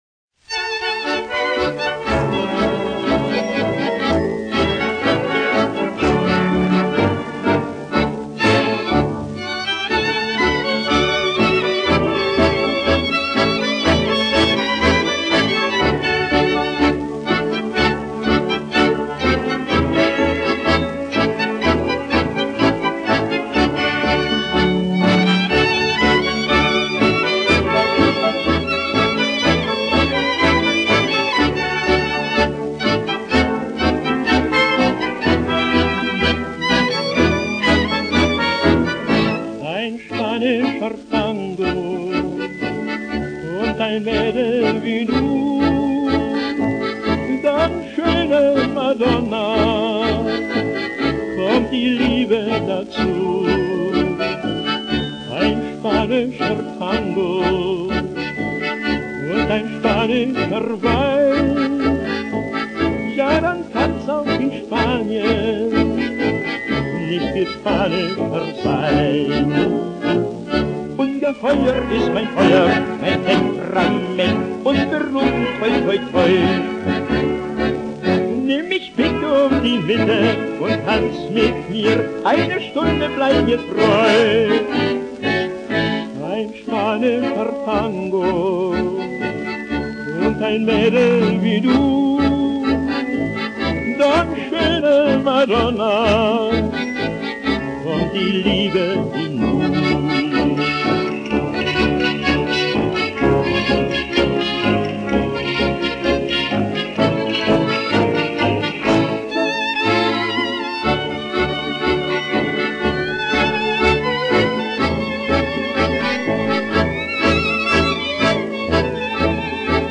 Одно испанское танго на немецком